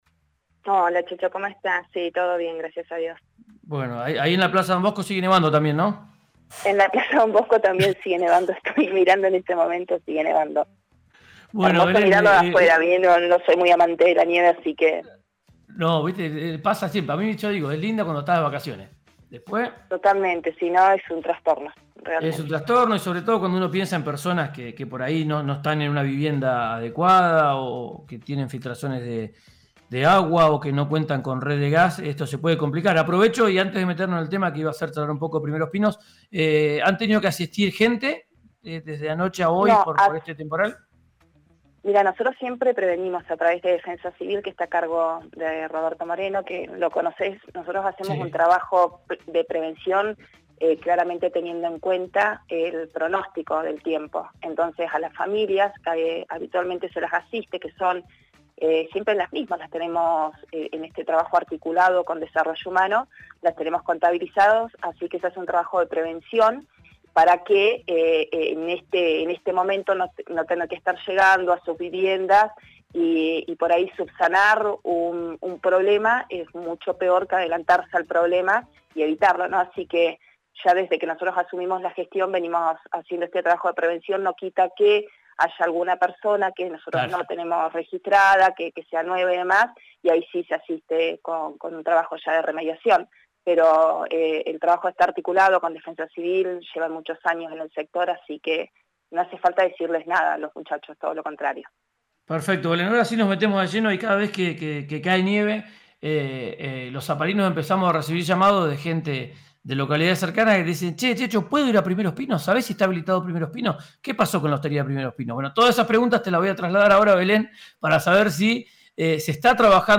Escuchá a Belén Aragón, secretaria de Gobierno de Zapala, en RÍO NEGRO RADIO:
En diálogo con RÍO NEGRO RADIO, la secretaria de Gobierno Belén Aragón, explicó que se está trabajando para habilitar el parque de nieve de Primeros Pinos a principios de julio. Y remarcó la importancia de la llegada de turistas de Río Negro y Neuquén a la localidad de Zapala.